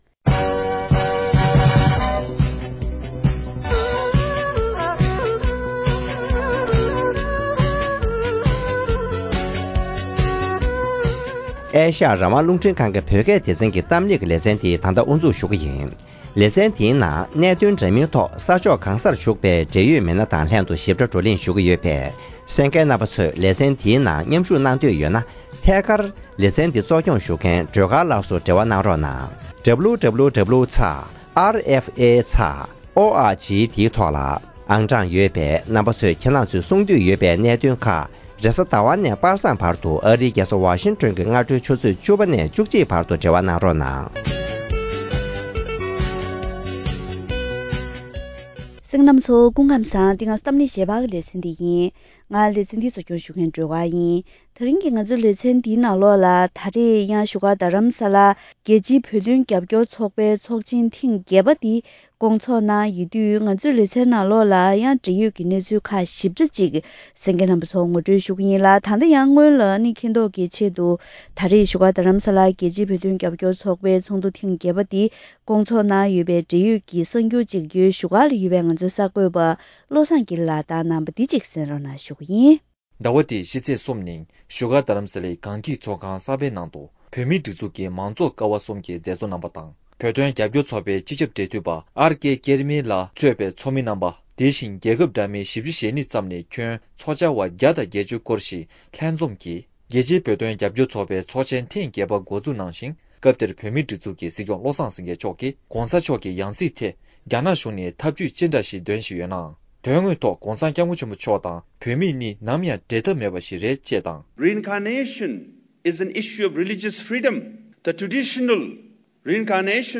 ཚོགས་འདུར་མཉམ་ཞུགས་གནང་མཁན་མི་སྣ་ཁག་ཅིག་དང་ལྷན་དུ་